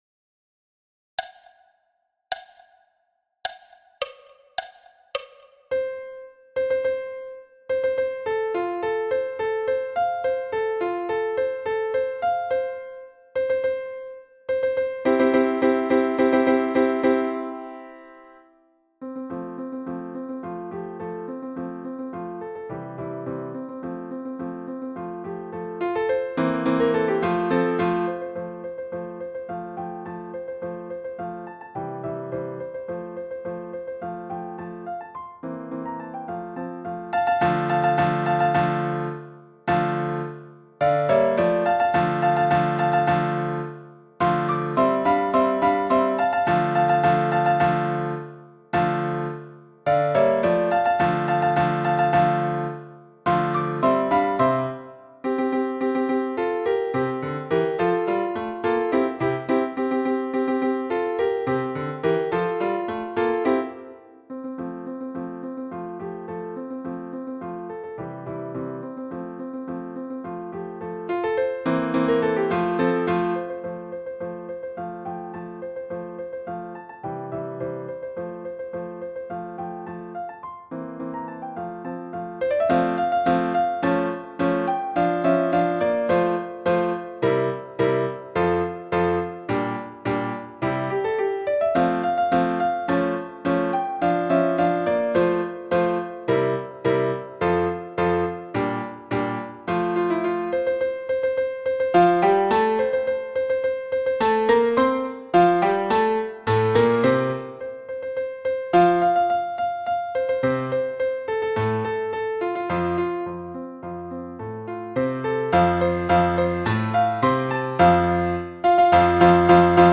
William Tell – piano à 106 bpm
William-Tell-piano-a-106-bpm.mp3